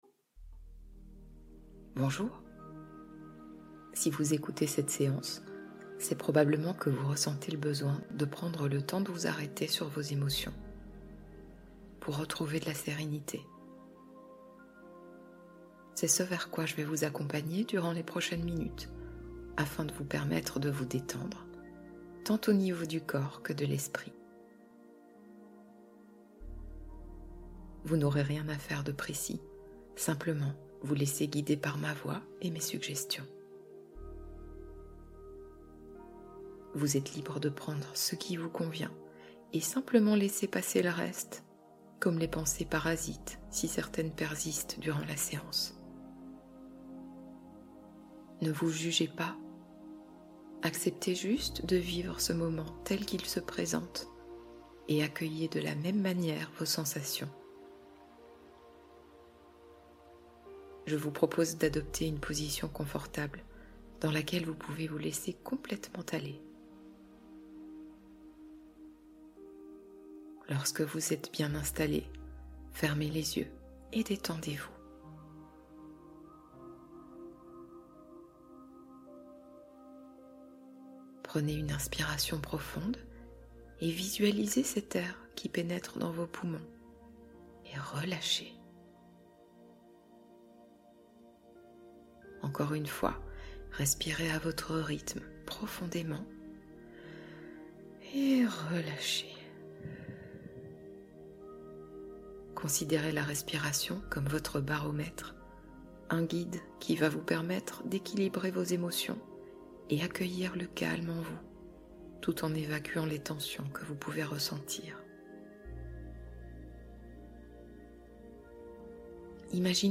Paix Intérieure : Séance de relaxation pour effacer les tensions